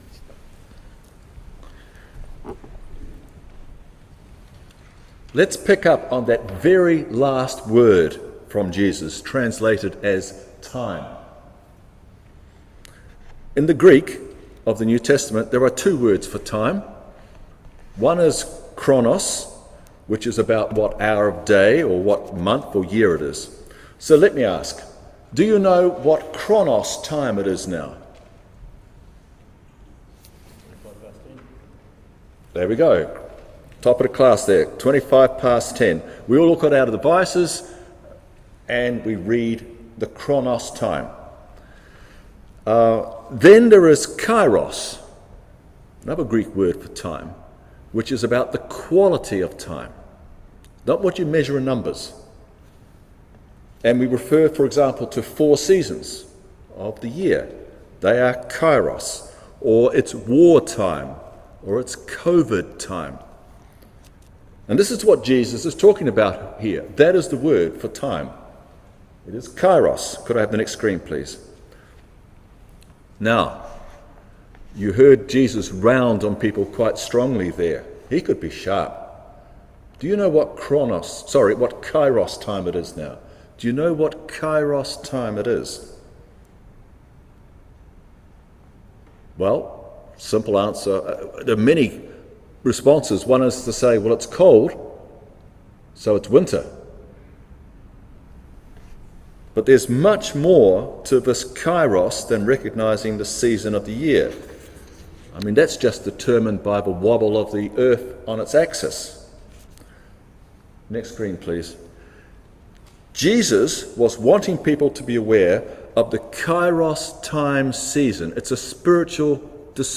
Passage: Isaiah 5:1-7, Hebrews 11:29 - 12:2, Luke 12:49-56 Service Type: Holy Communion